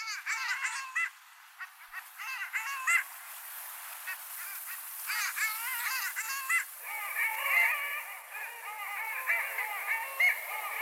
Sjöfåglarnas läten
Alfågel